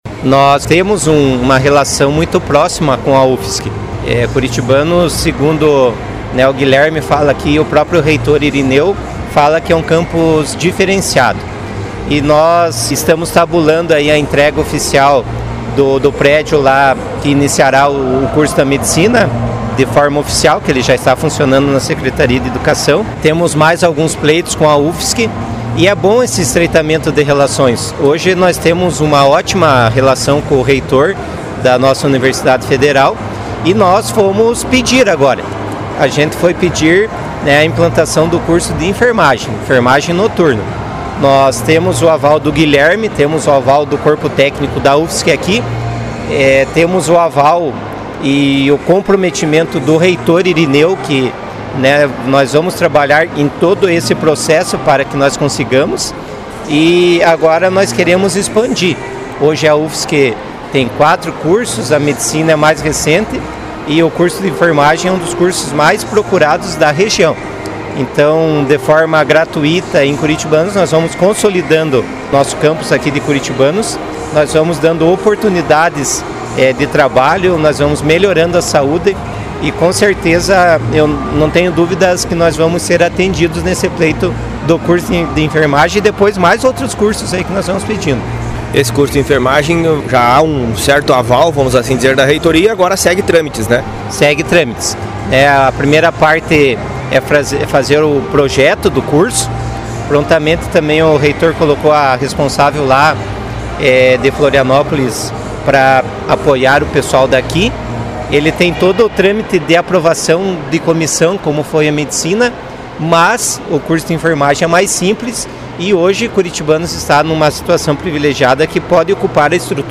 O prefeito comentou o pedido a nossa reportagem.